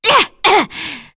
Demia-Vox_Attack4.wav